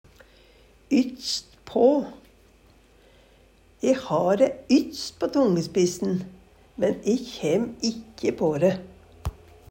ytst på - Numedalsmål (en-US)